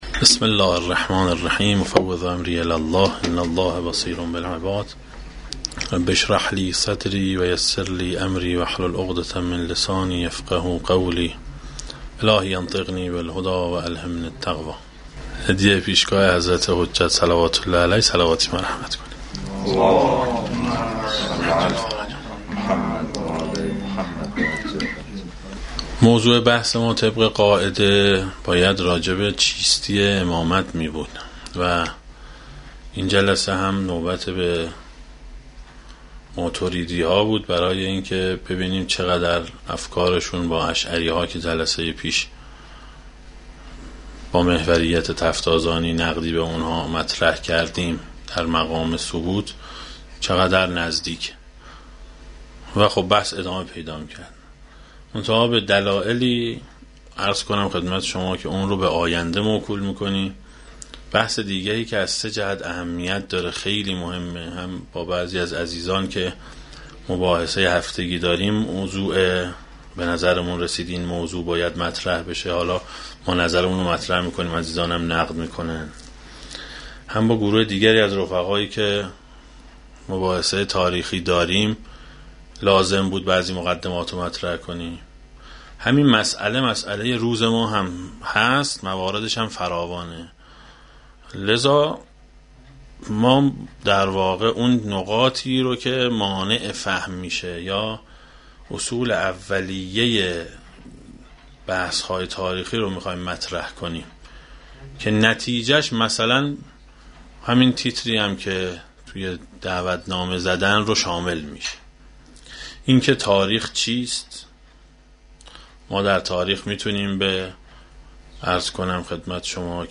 شایان ذکر است، پس از جلسه پرسش و پاسخ و نقد و بررسی مباحث صورت گرفت.